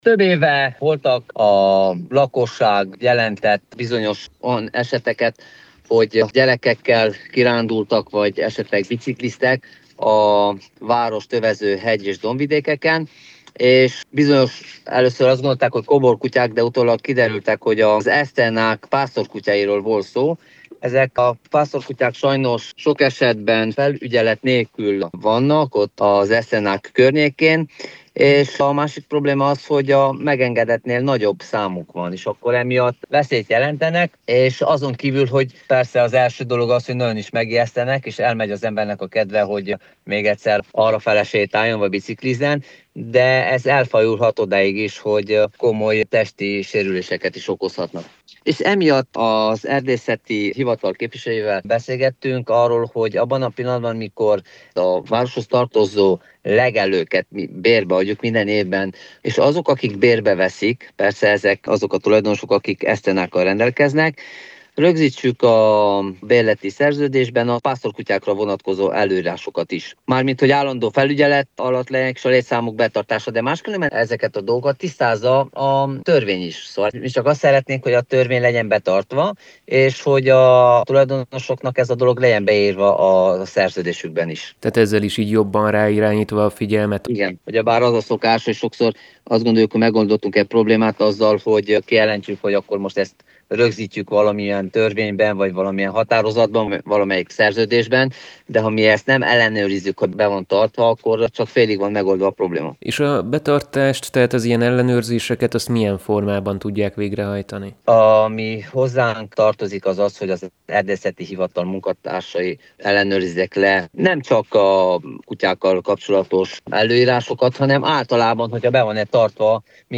Nagybánya alpolgármestere, Pap Zsolt István rádiónknak elmondta: a városi Erdészeti Hivatal képviselőit arra kérte, hogy a legelők bérszerződésében rögzítsék a pásztorkutyákra vonatkozó előírásokat is, ezek állandó felügyeletét és a létszámuk betartását.